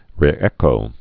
(rĭ-ĕkō)